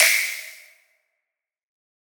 taiko-normal-hitwhistle.ogg